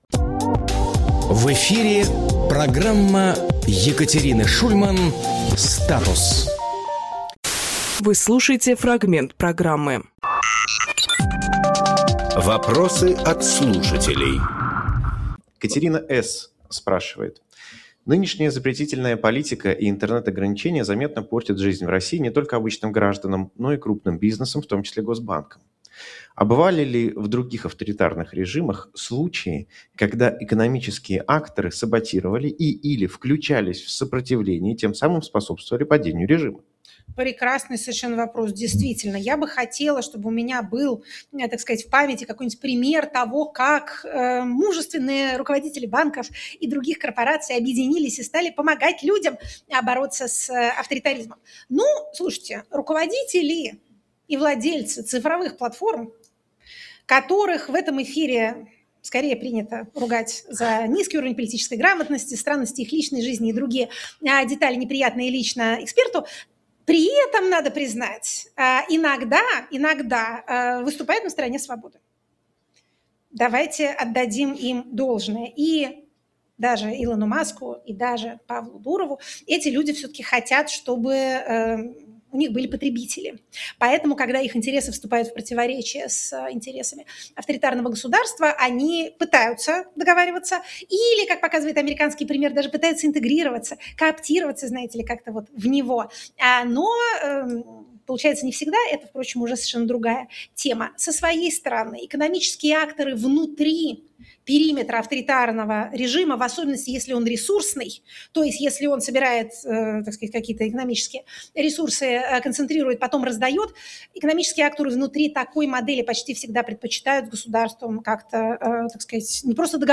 Максим Курниковглавный редактор «Эха», журналист
Екатерина Шульманполитолог
Фрагмент эфира от 07.04.26